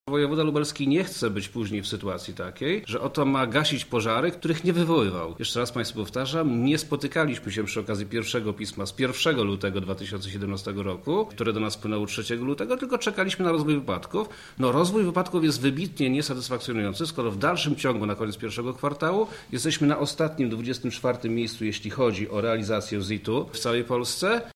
O szczegółach mówi wojewoda Lubelski Przemysław Czarnek